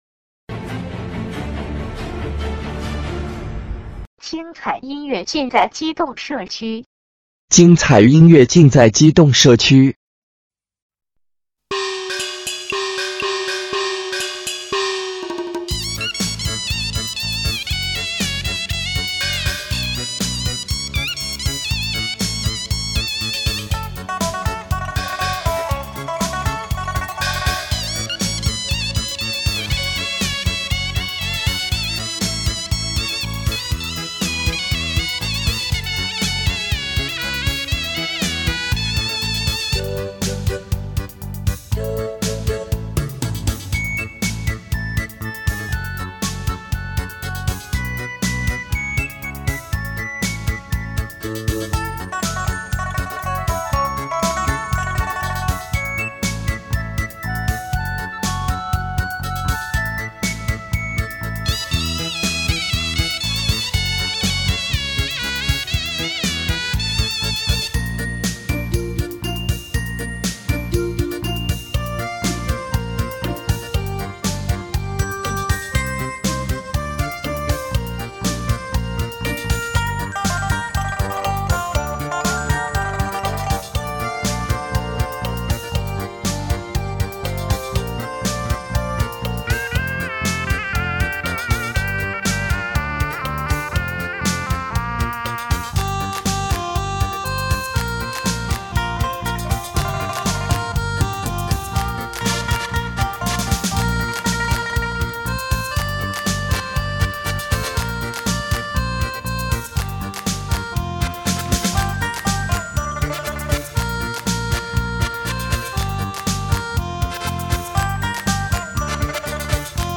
平四